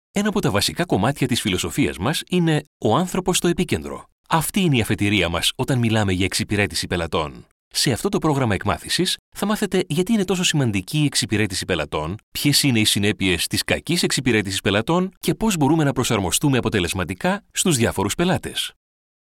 Sprechprobe: eLearning (Muttersprache):
The amazing Greek voice